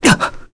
Arch-Vox_Damage_kr_01.wav